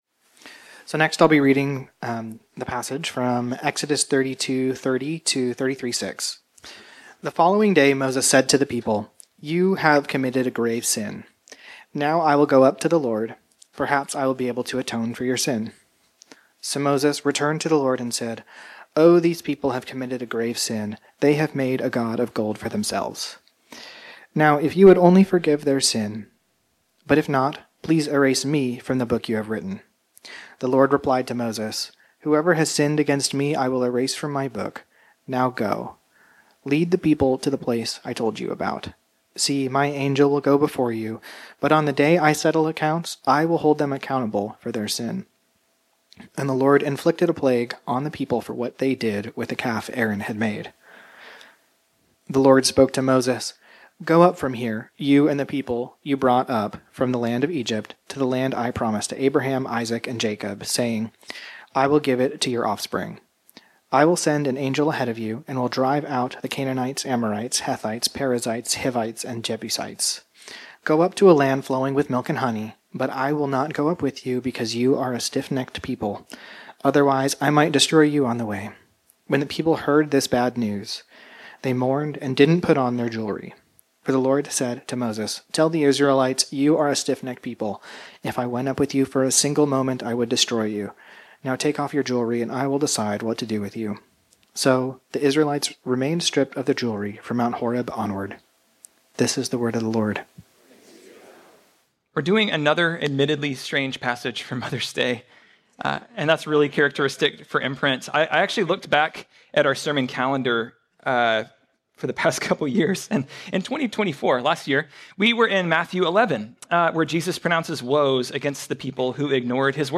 This sermon was originally preached on Sunday, May 11, 2025.